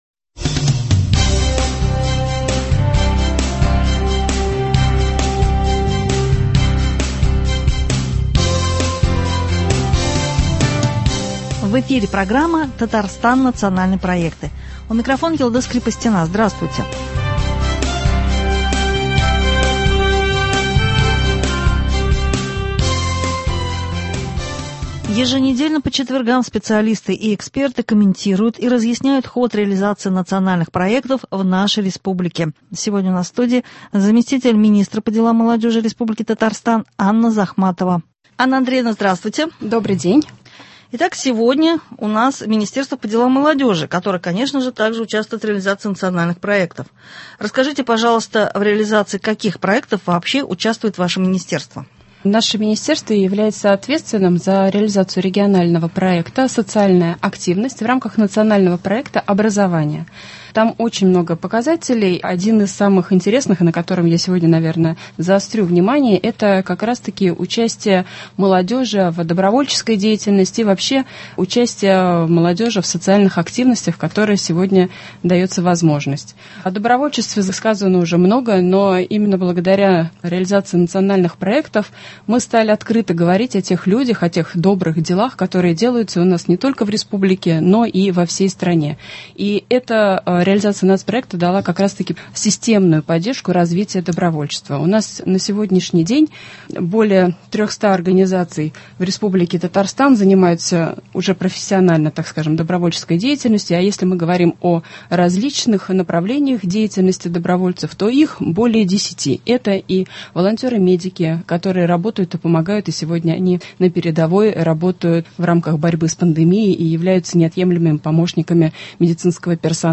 Еженедельно по четвергам специалисты комментируют и разъясняют ход реализации Национальных проектов в нашей республике.
И сегодня у нас в студии зам. министра по делам молодежи Анна Захматова.